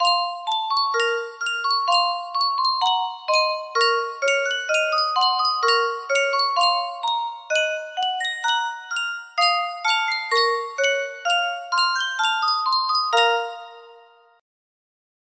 Yunsheng Music Box - Kurodabushi 6499 music box melody
Full range 60